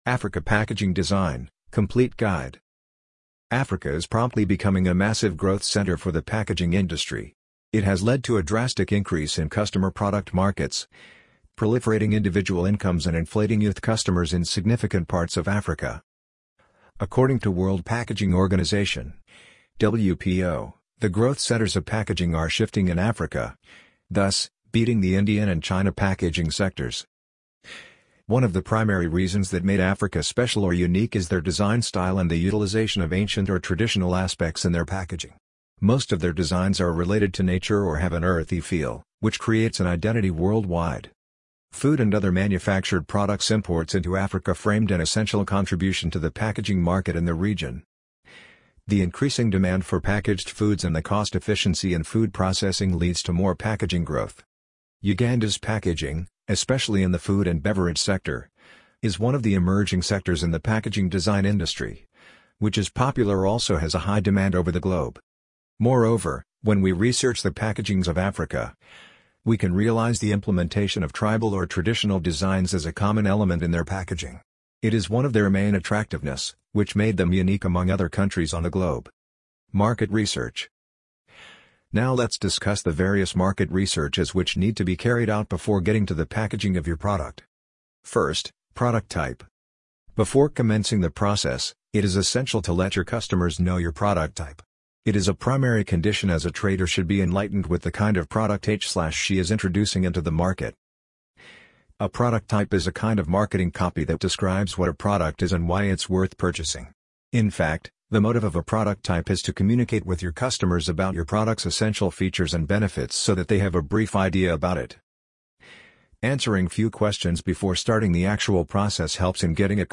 amazon_polly_5799.mp3